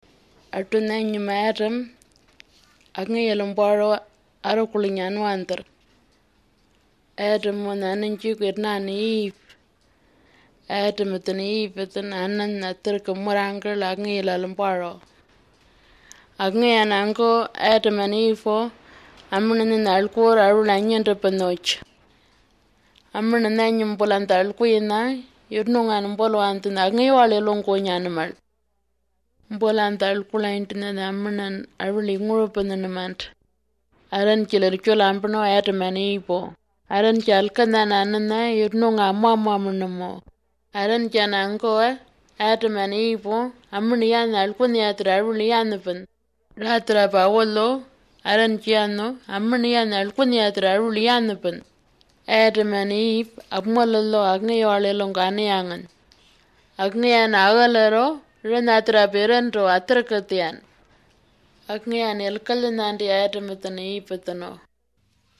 16 May 2011 at 1:40 am I hear a lot of retroflex consonants, and it sounds like a cross between an Australian Aboriginal language and a Dravidian language.